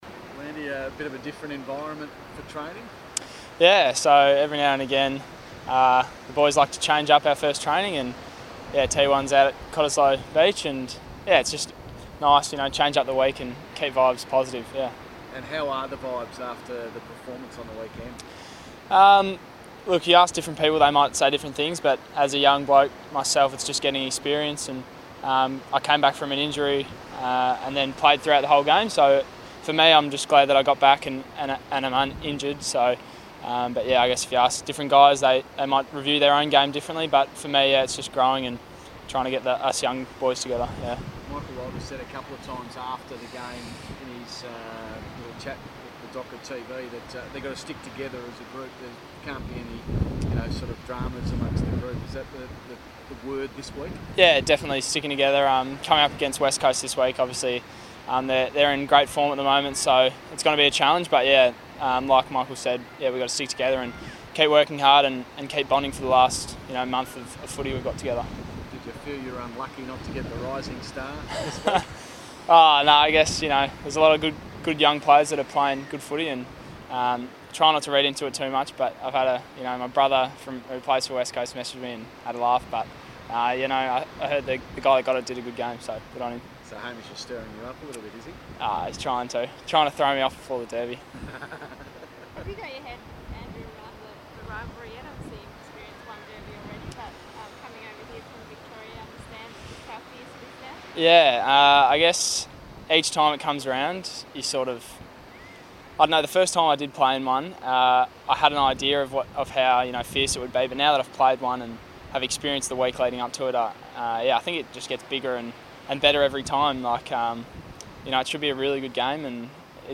Andy Brayshaw media conference - July 31 2018
Andy Brayshaw chats to media at prior to training at Cottesloe beach.